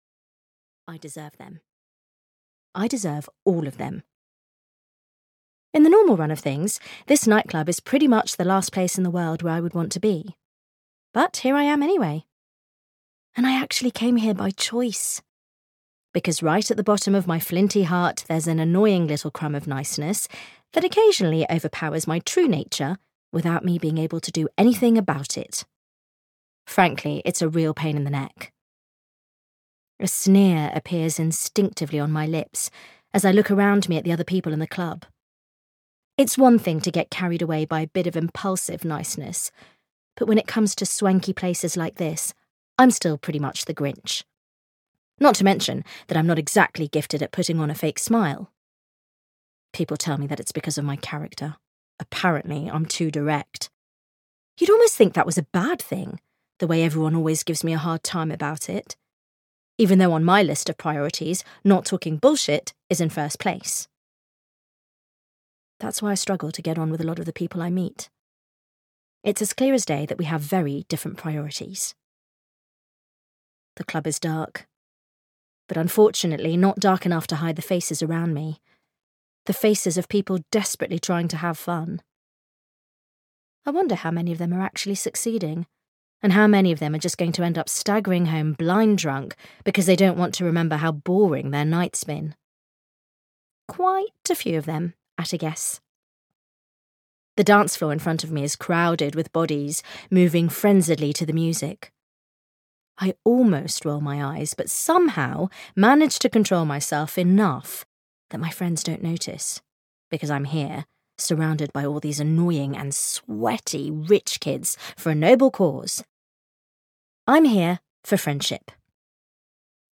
Mad About You (EN) audiokniha
Ukázka z knihy